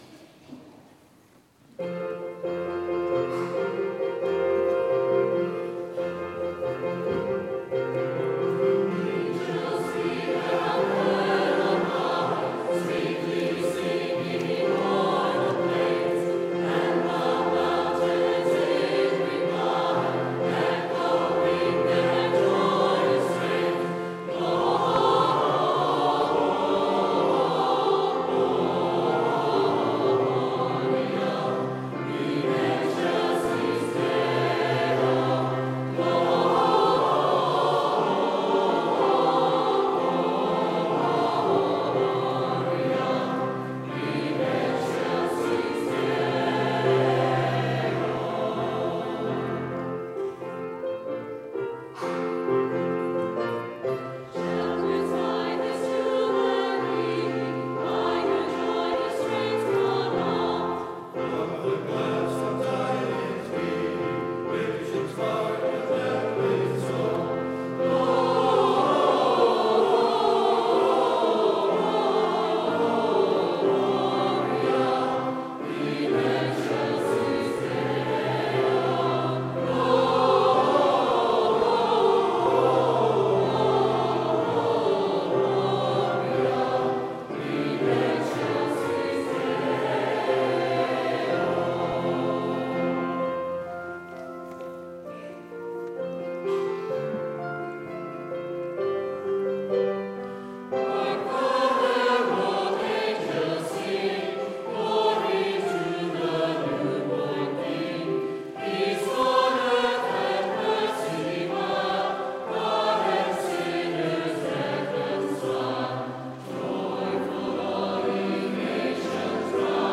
Cathedral Choir, Living Word, Angelorum, Cathedral Clergy Choir, Diocesan Choir, December, 2023